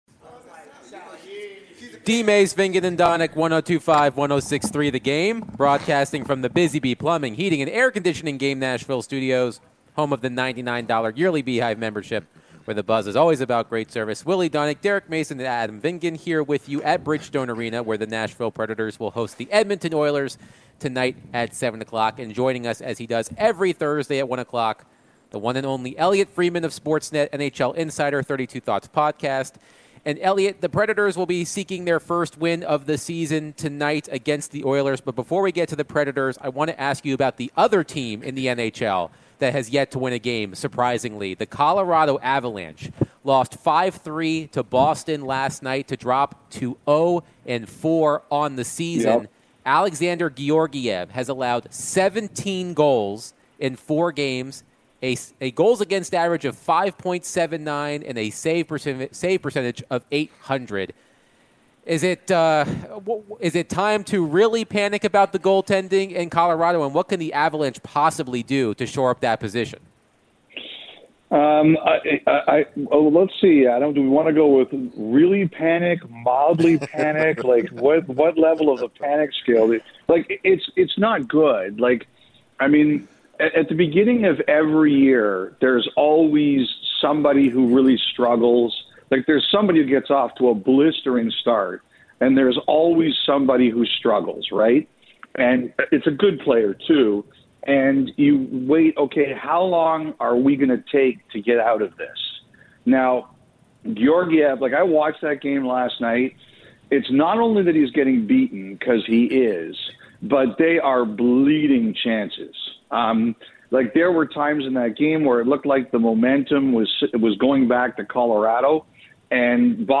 In the final hour of DVD, NHL Insider Elliotte Friedman joined the show to share his thoughts on the Predators' rough start to the season. Is it time to hit the panic button after the first 3 winless games? When will the Preds get back on track?